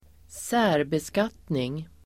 Ladda ner uttalet
särbeskattning substantiv, individual (separate) taxation Uttal: [²s'ä:rbeskat:ning] Böjningar: särbeskattningen Definition: beskattning av äkta makars inkomster var för sig Förklaring: Särbeskattning gör att båda makarna måste deklarera sina egna inkomster.